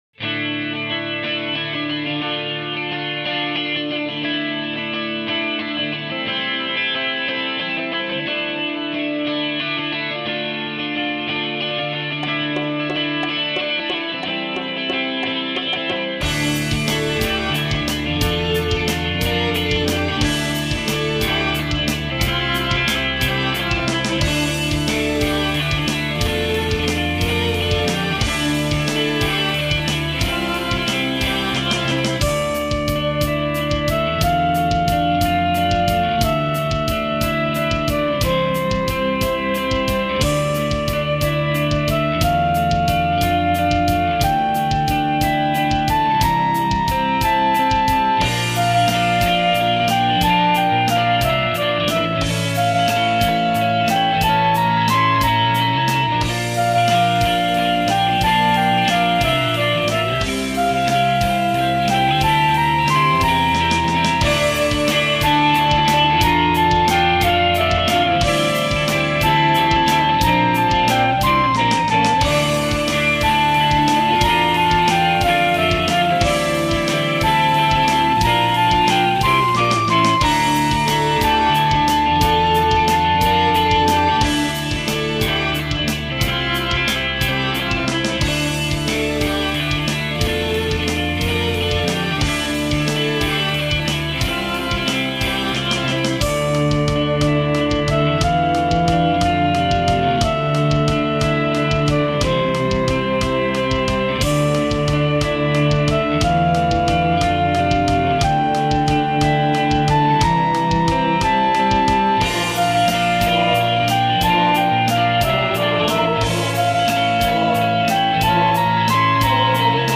■■デモ音源■■